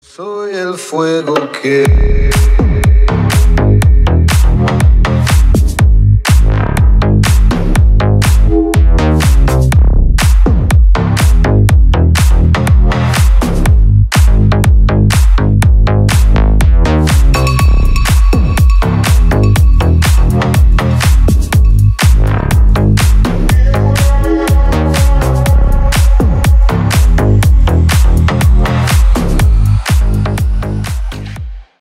• Качество: 320 kbps, Stereo
Электроника
без слов